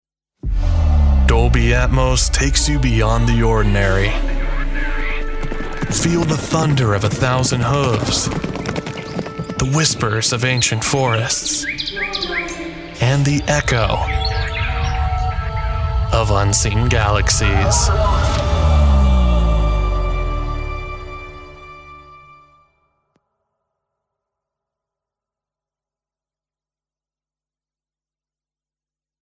Male
Radio Imaging
Words that describe my voice are young voice over, american voice over, male voice over.